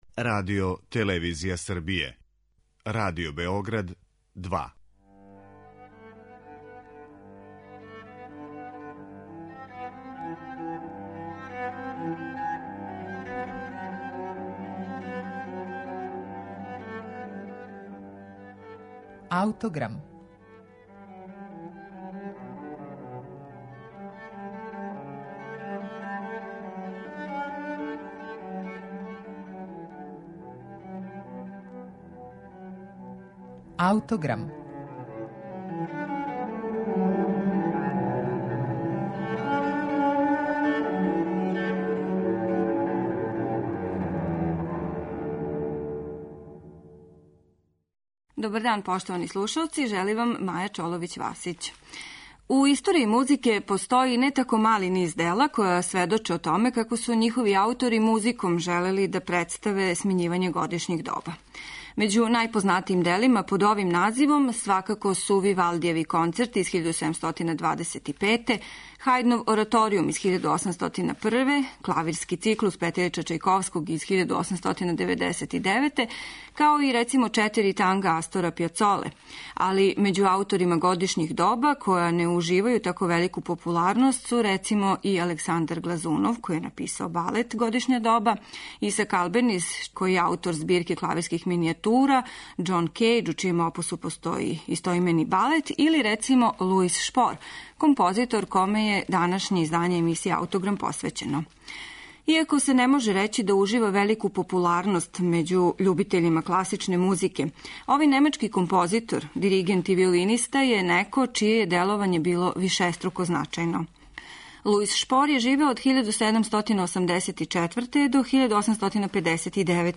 Уобичајена четири става груписана су у два одсека: I и II, као и III и IV став, спојени су прелазима, а у складу са тематиком нису изостали ни карактеристични мотиви попут дрвених дувача којима се на крају I става (Зиме) најављује буђење птица у Пролећу, или пак ловачких мотива у хорнама којима почиње последњи став - Јесен.